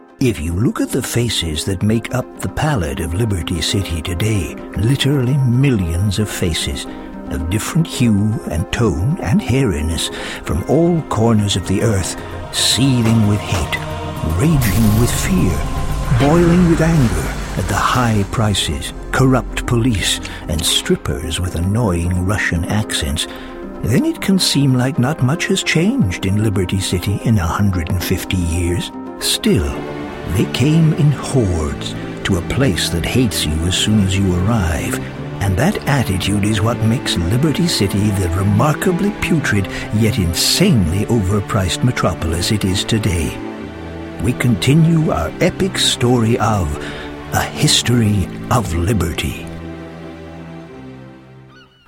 Below are short samples from selected VO, Commercial, and dramatic pieces